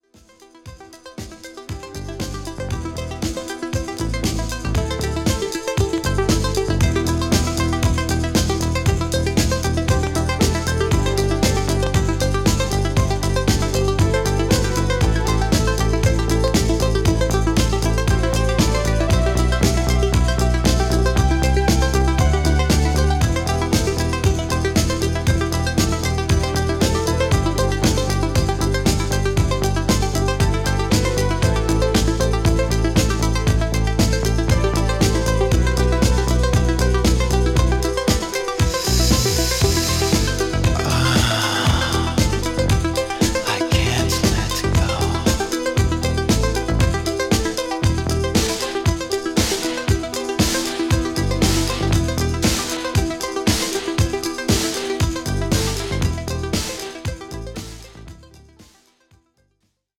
‘80S/’90’sハウス名曲集。